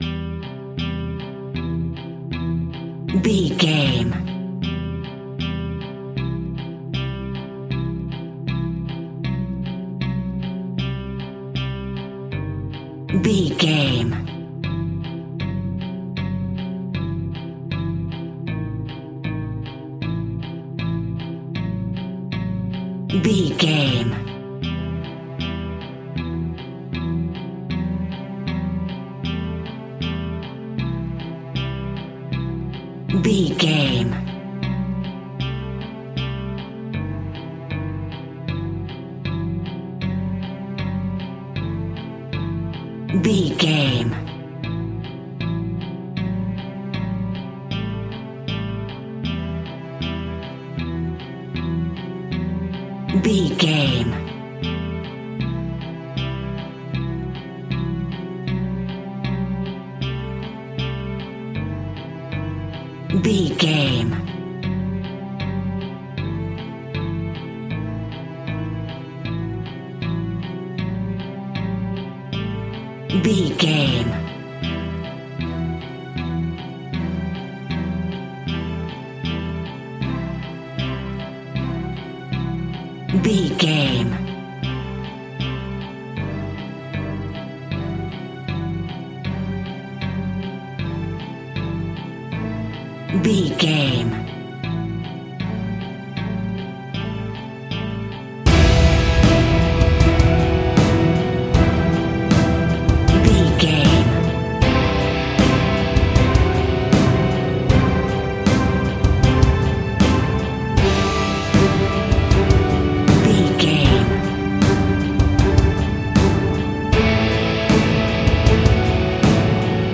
In-crescendo
Thriller
Aeolian/Minor
scary
tension
ominous
dark
suspense
haunting
eerie
strings
brass
percussion
violin
cello
drums
cymbals
timpani